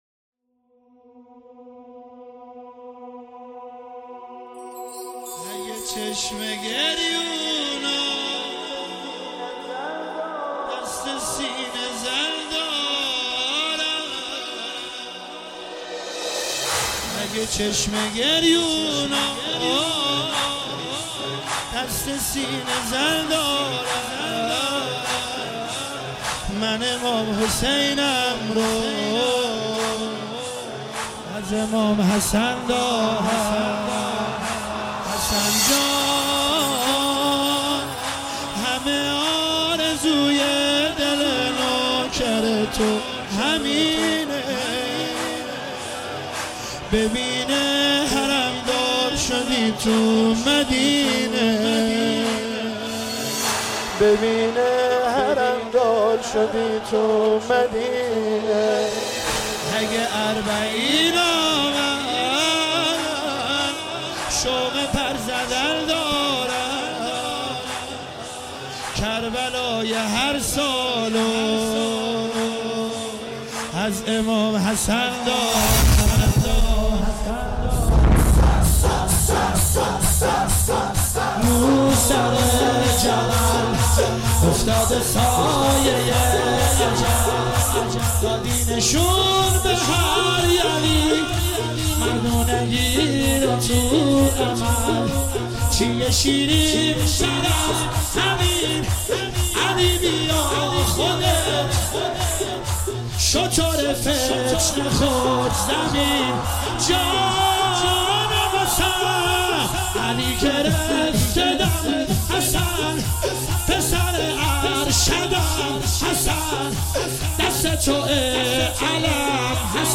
مداحی زیبا و دلنشین